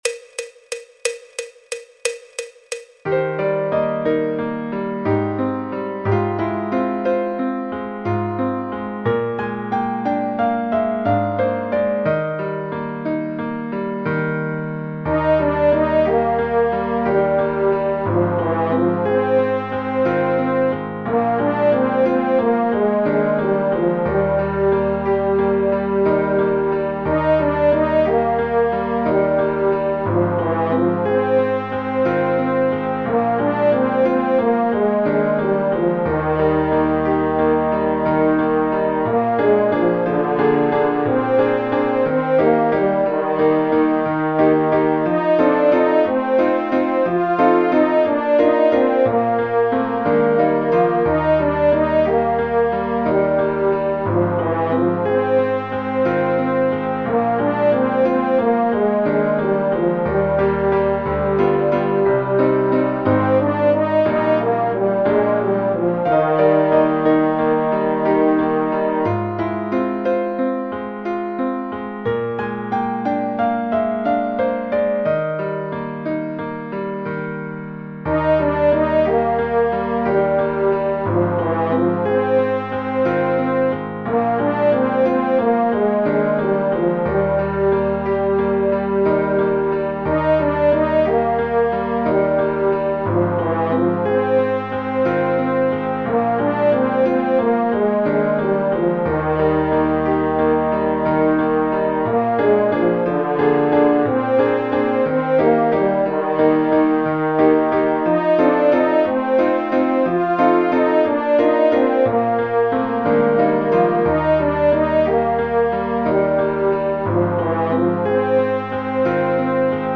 El MIDI tiene la base instrumental de acompañamiento.